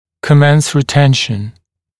[kə’mens rɪ’tenʃn][кэ’мэнс ри’тэншн]начинать ретенцию, переходить к этапу ретенции